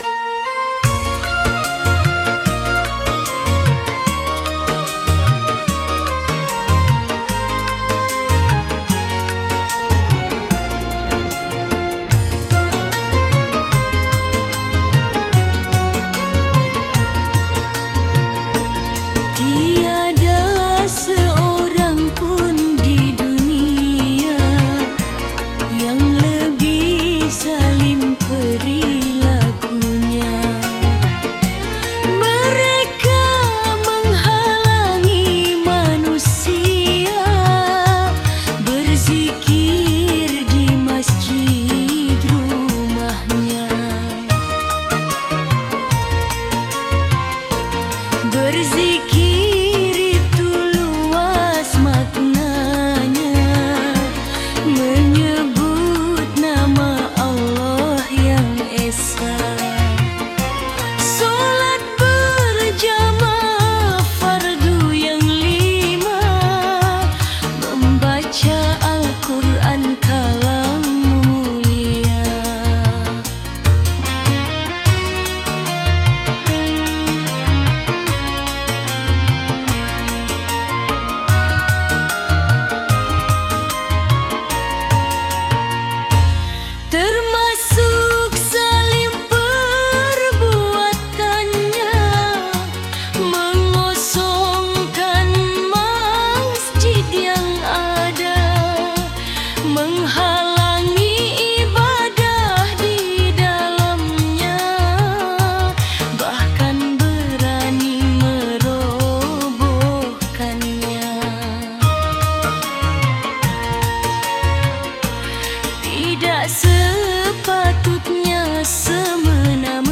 Dengarkan musik syahdunya